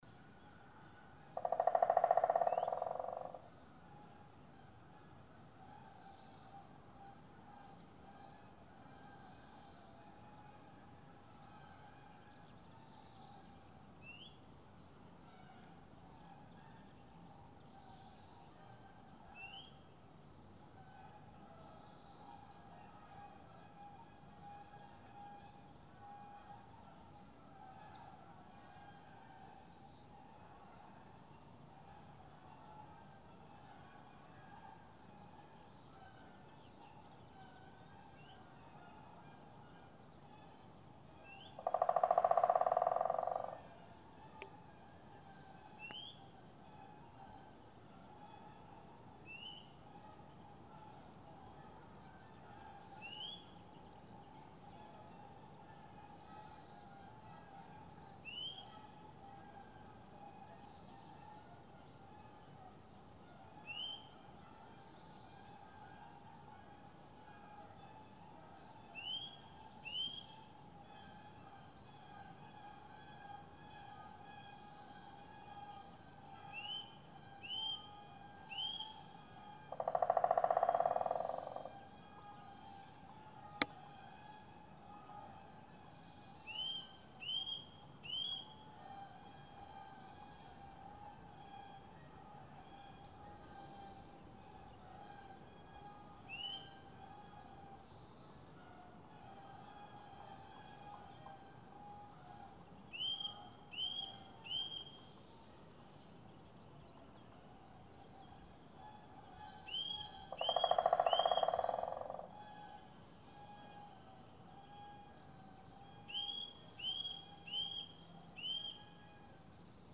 アカゲラのドラミング
woodpecke_drumming.mp3